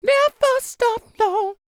DD FALSET085.wav